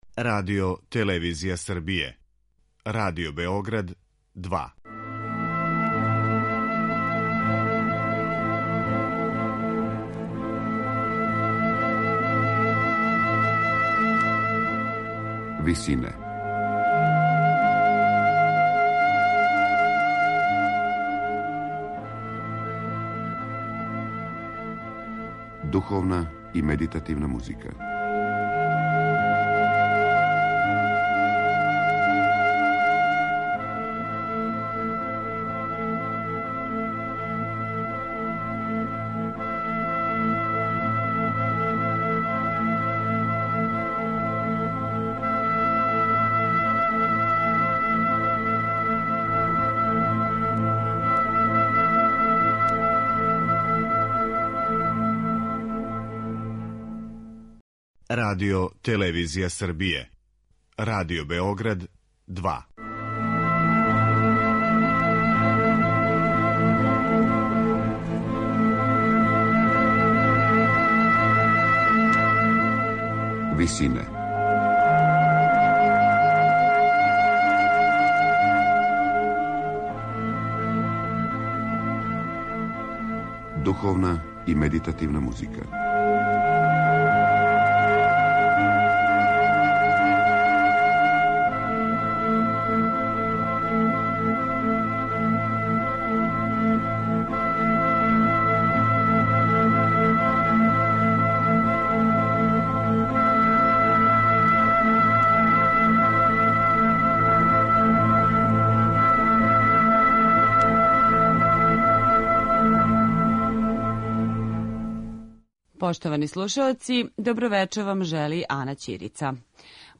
Јапанске мелодије за флауту и харфу
флаутиста
харфисткиња